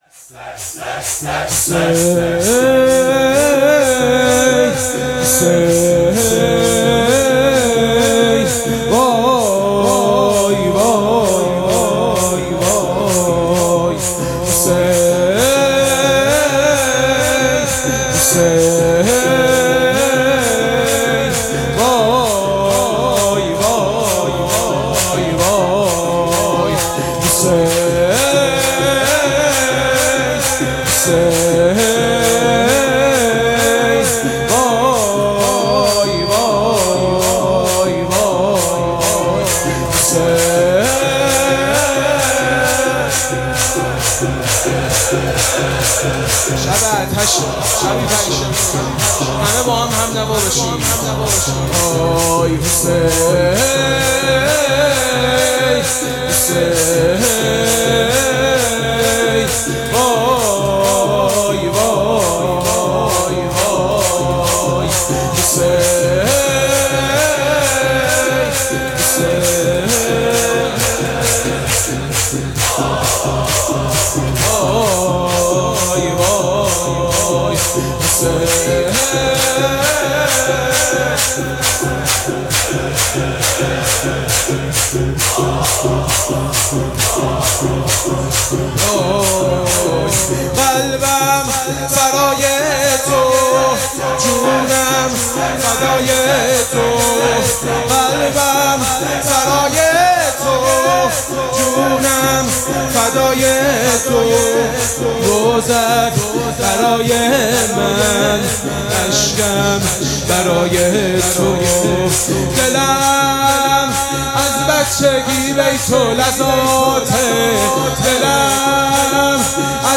مراسم عزاداری شب دهم محرم الحرام ۱۴۴۷
شور
مداح